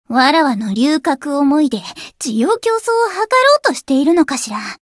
灵魂潮汐-敖绫-互动-不耐烦的反馈2.ogg